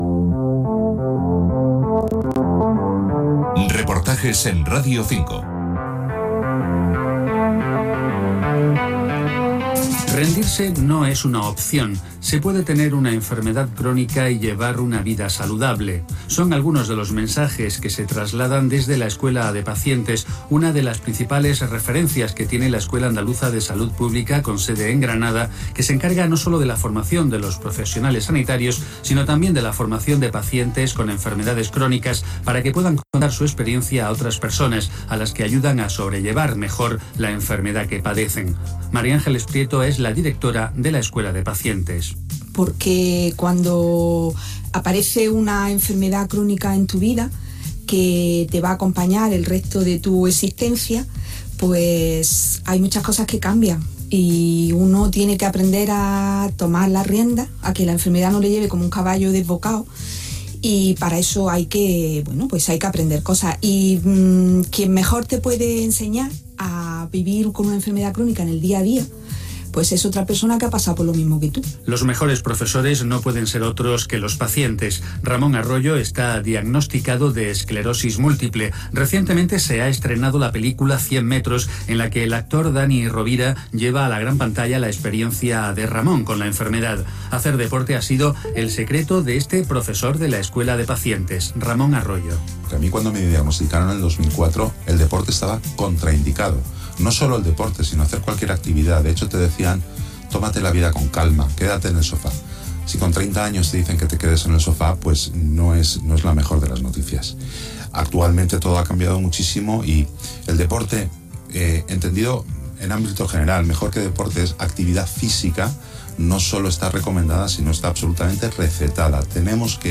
Reportaje realizado en el transcurso del IV Congreso de la Escuela de Pacientes.